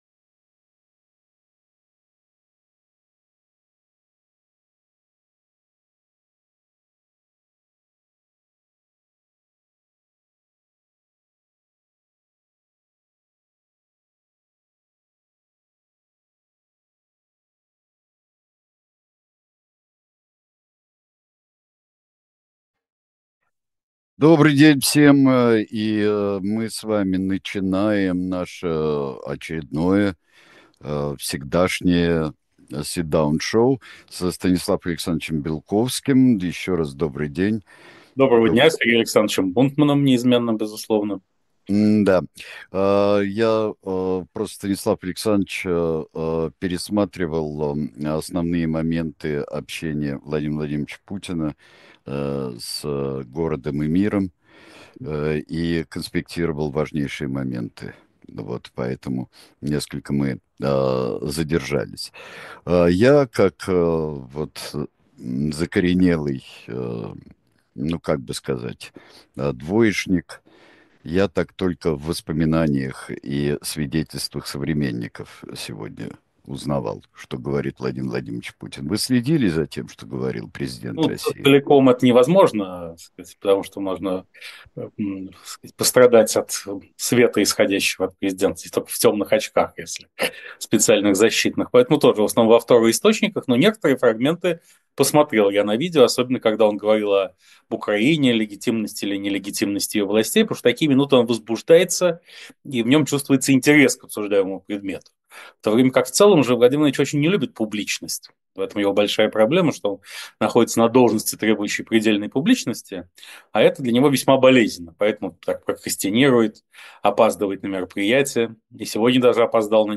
Эфир ведет Сергей Бунтман